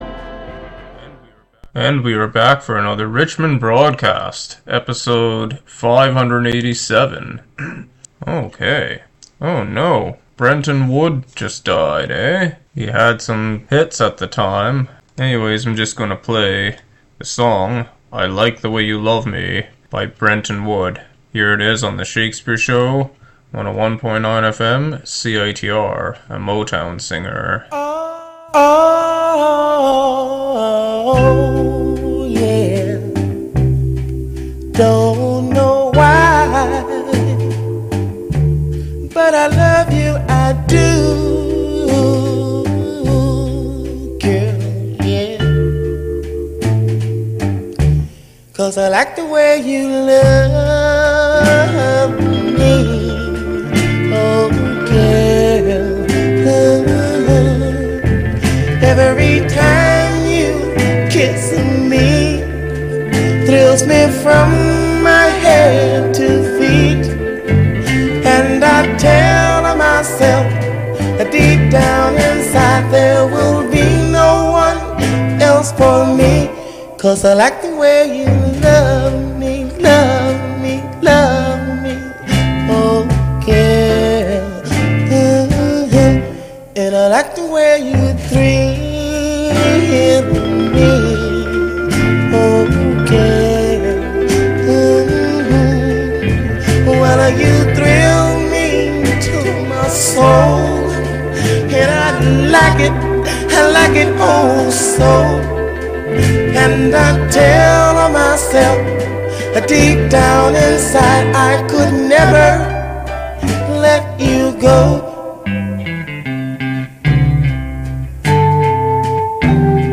an eclectic mix of music